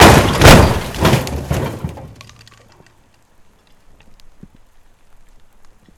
mercedes_benz_dropped_1m_on_concrete_ls-5_2.ogg